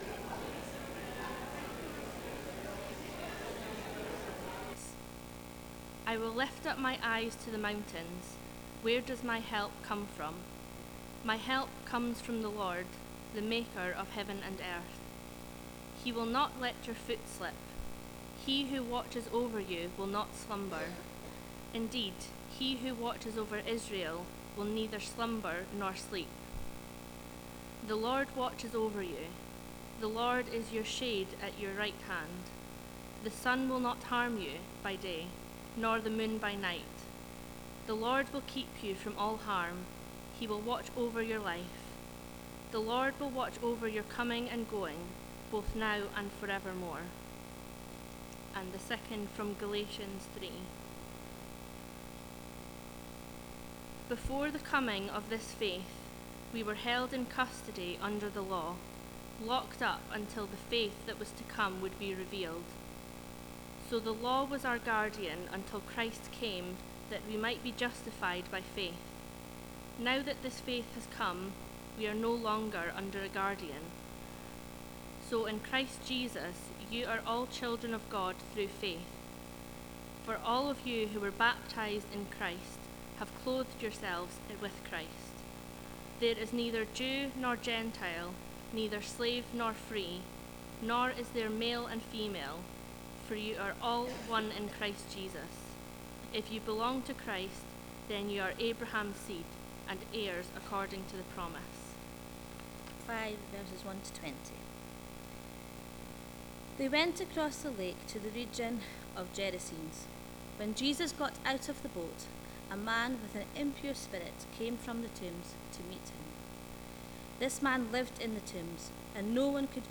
Mark's Gospel Passage: Psalm 121, Galatians 3:23-29, Mark 5:1-20 Service Type: Sunday Morning « Friendship